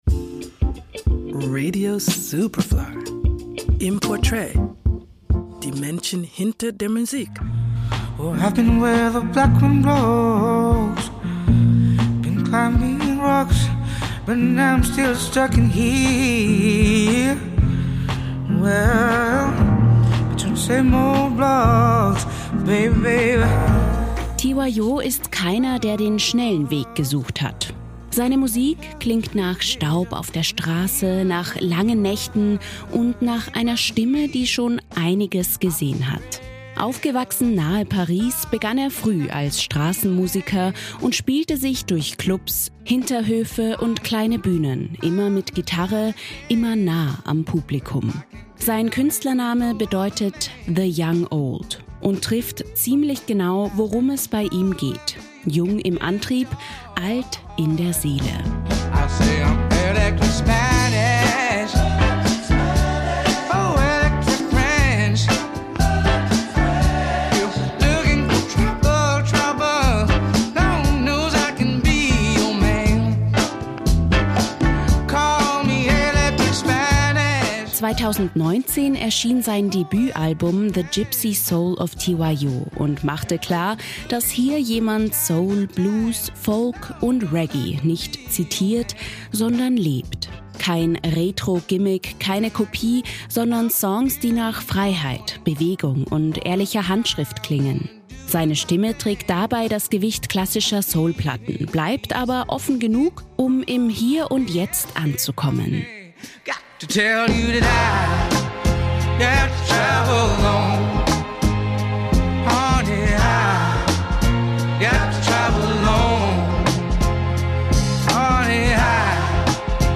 Seine Musik klingt nach Staub auf der Straße, nach langen Nächten und nach einer Stimme, die schon einiges gesehen hat.